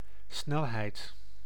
Ääntäminen
IPA: [snɛl.ɦɛjd]